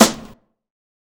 CDK Ye Snare.wav